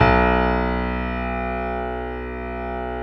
55p-pno05-B0.wav